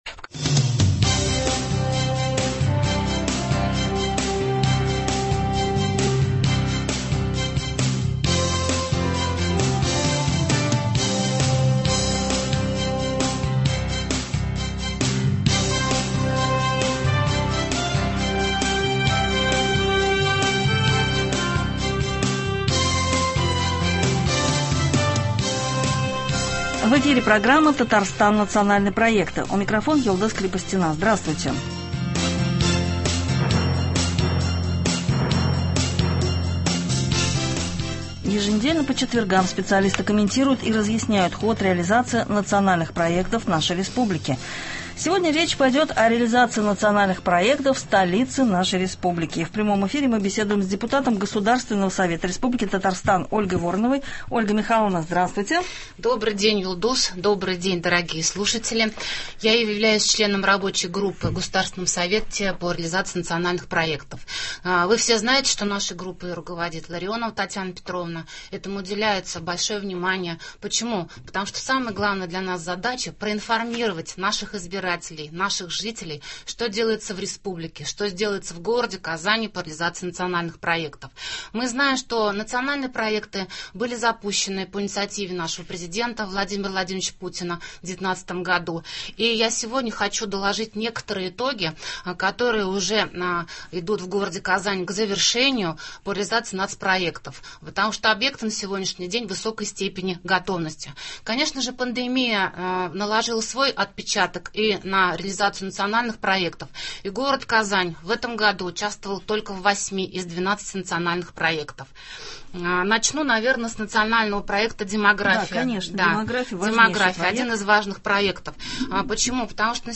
Еженедельно по четвергам специалисты комментируют и разъясняют ход реализации Национальных проектов в нашей республике. Сегодня речь пойдет о Казани, в прямом эфире беседуем с депутатом Госсовета Татарстана Ольгой Вороновой.